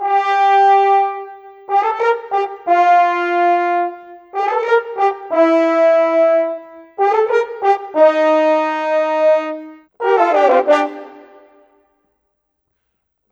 Rock-Pop 07 Horns 01.wav